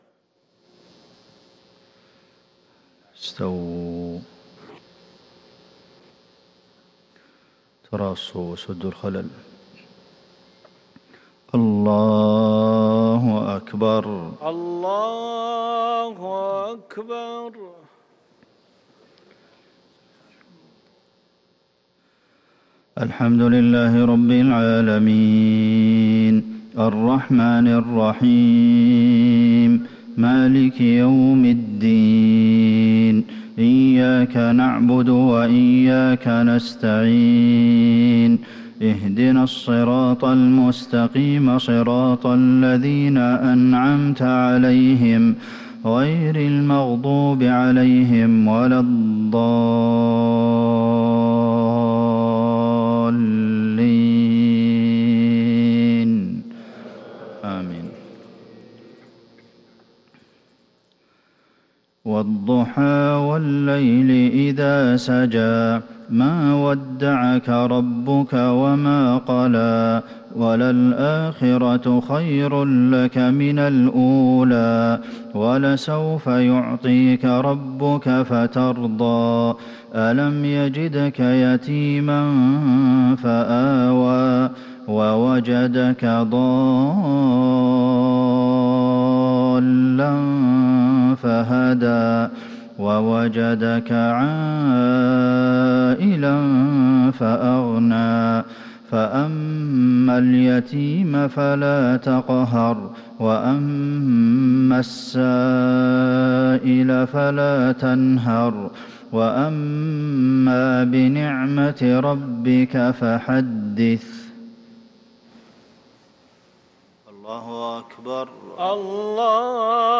مغرب الأحد 3-8-1443هـ سورتي الضحى و الشرح | Maghrib prayer from Surat Ad-Dhuhaa and Ash-Sharh 6-3-2022 > 1443 🕌 > الفروض - تلاوات الحرمين